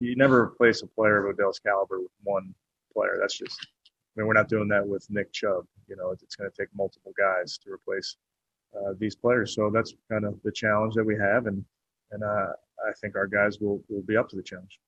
Head coach Kevin Stefanski shared his thoughts with the media on Beckham Jr.